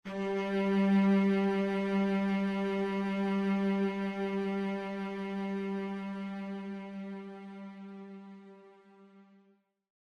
Нота: Соль первой октавы (G4) – 392.00 Гц
Note6_G4.mp3